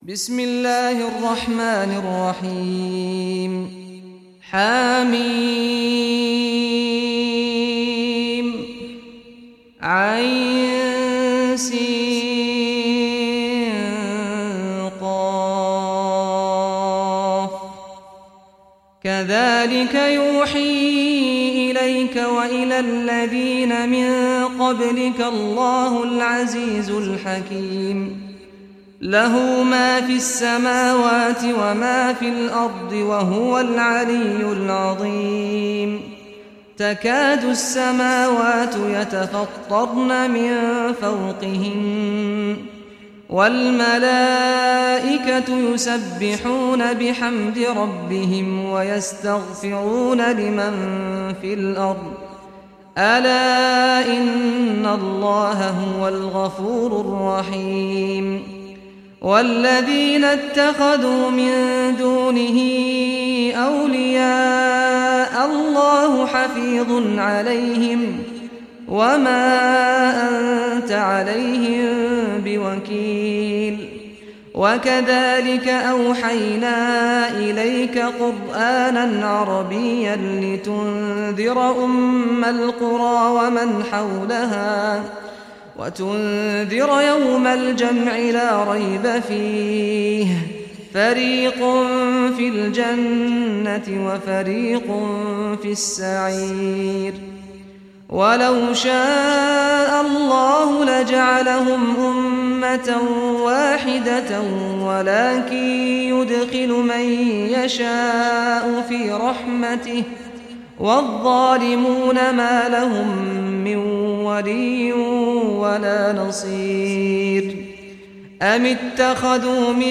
Surah Ash-Shuraa Recitation by Sheikh Saad Ghamdi
Surah Ash-Shuraa, listen or play online mp3 tilawat / recitation in Arabic in the beautiful voice of Sheikh Saad al Ghamdi.